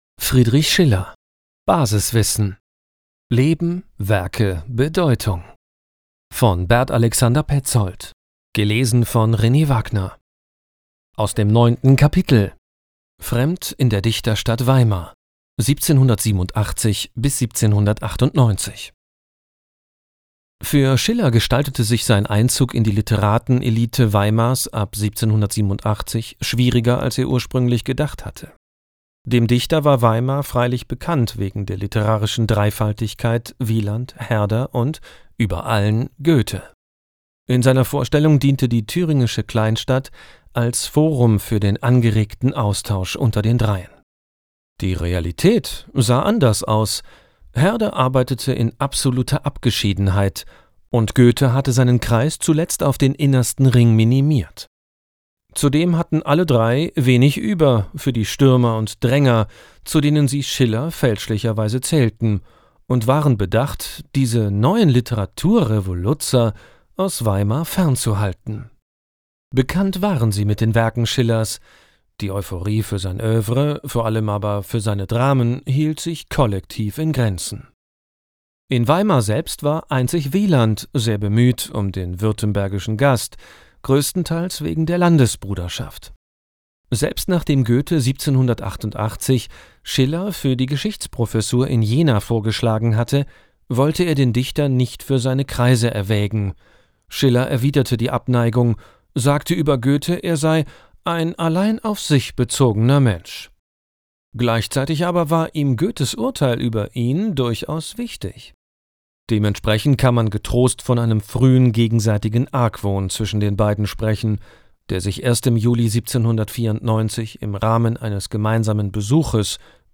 Hörbuch